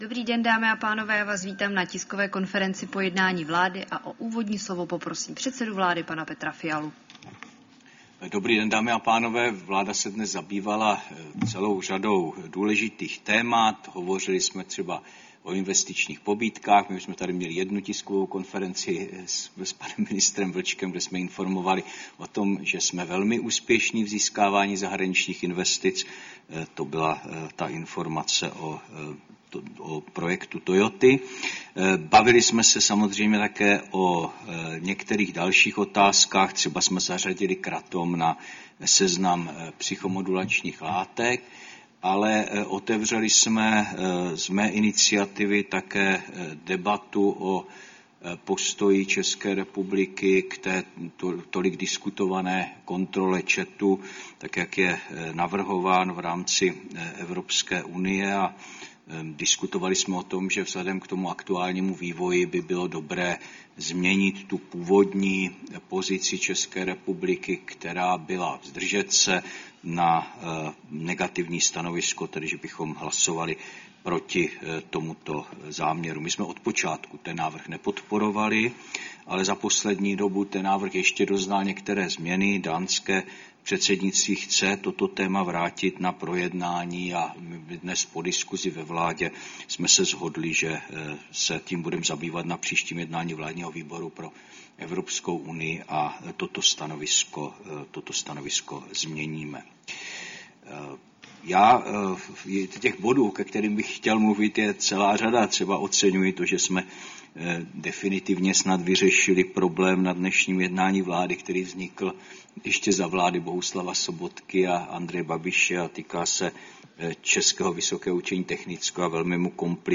Tisková konference po jednání vlády, 3. září 2025